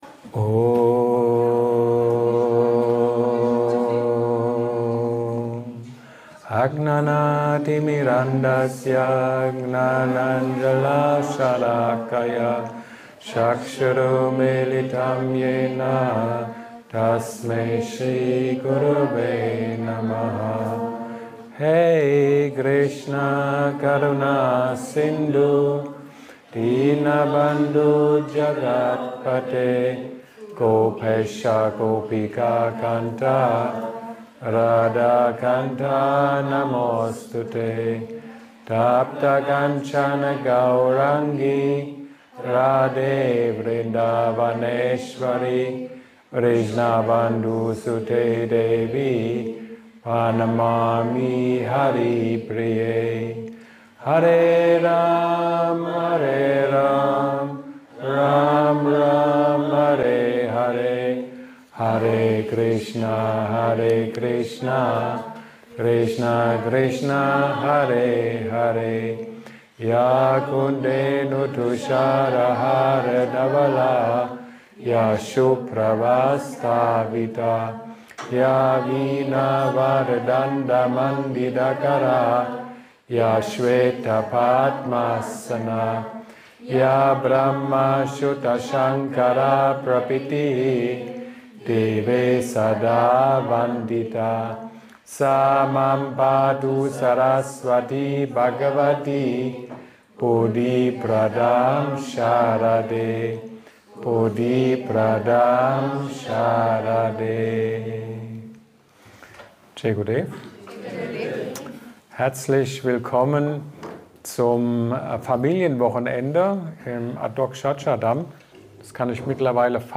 Willkommen zur Sangha und Devotee Woche im Adhokshaja Dham Ashram in Österreich.